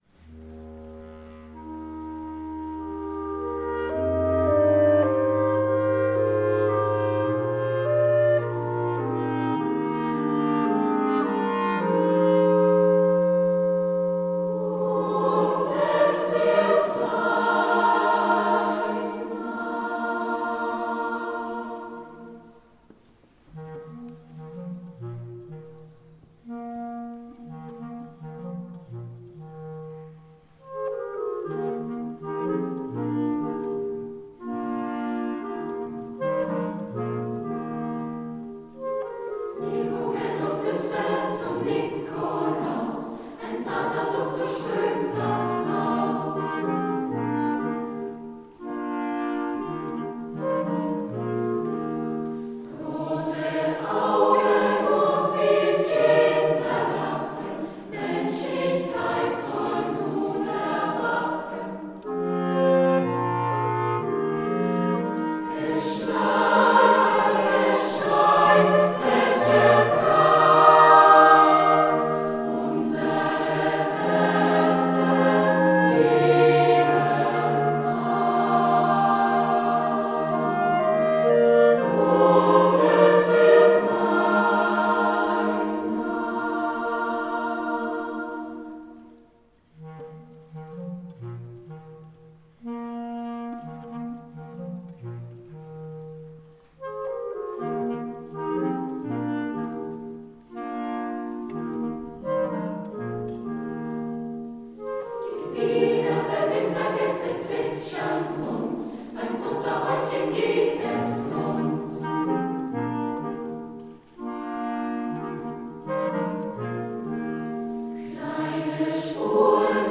Hier finden Sie das Tondokument der Uraufführung.